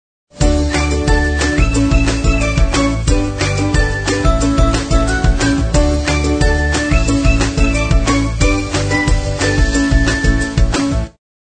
描述：一个有趣的，朗朗上口，乐观向上和令人振奋的声轨与欢快和明亮的口哨旋律。 幽默有趣的音乐。